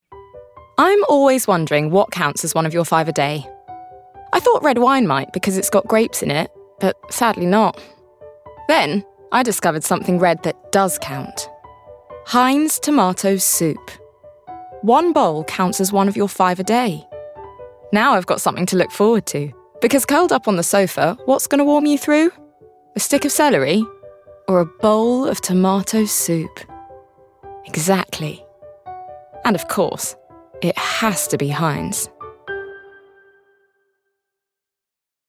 Heinz - Energised, Enthusiastic, Happy